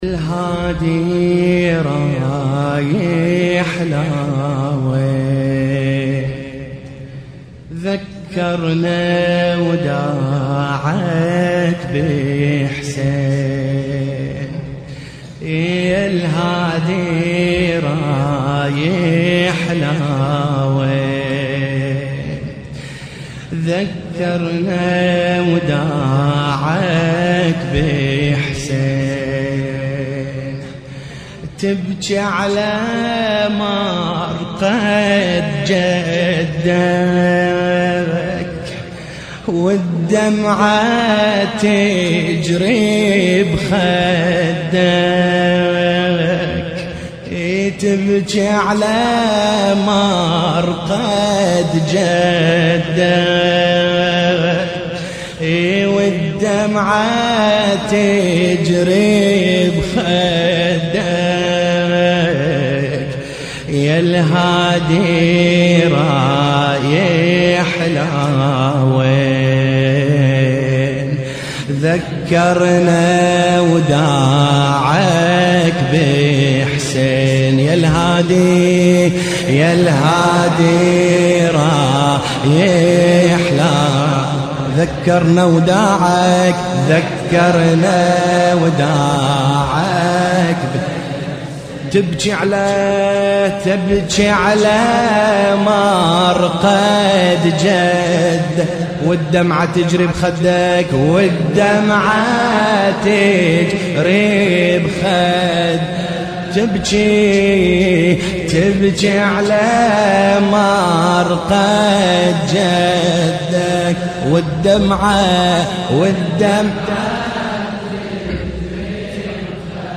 مراثي